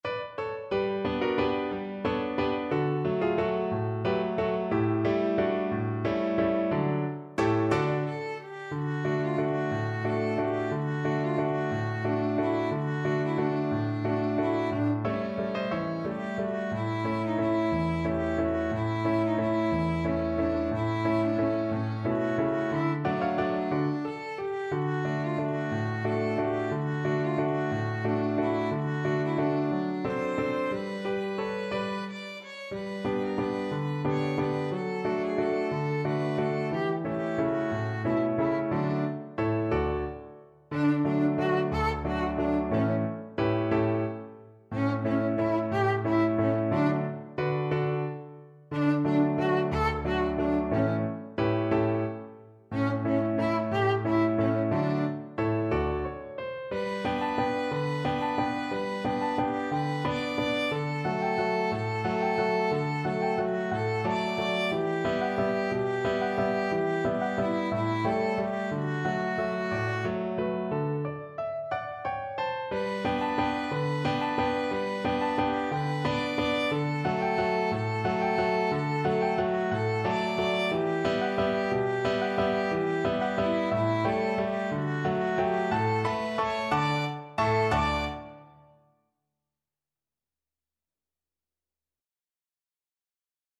Violin
D major (Sounding Pitch) (View more D major Music for Violin )
3/4 (View more 3/4 Music)
One in a bar .=c.60
Classical (View more Classical Violin Music)
Mexican